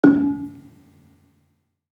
Gambang-D#3-f.wav